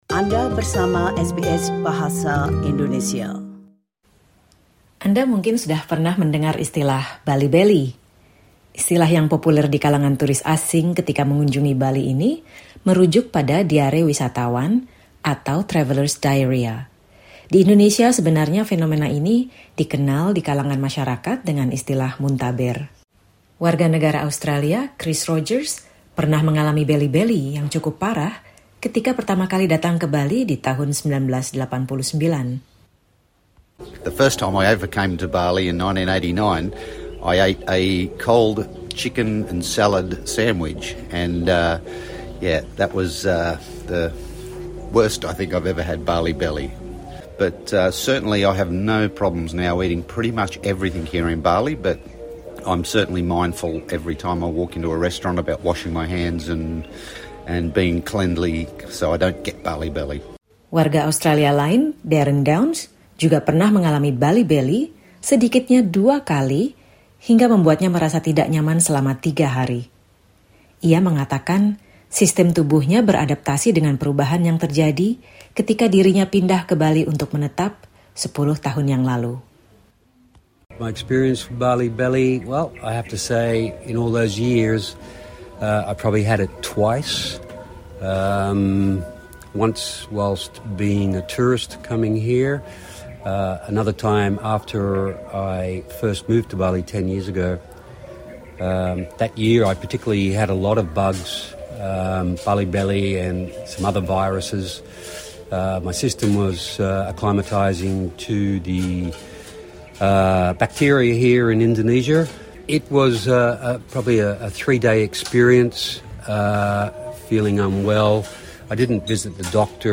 Informasi yang diungkapkan dalam wawancara ini bersifat umum, bukan saran kesehatan, dan mungkin tidak sesuai dengan situasi pribadi Anda.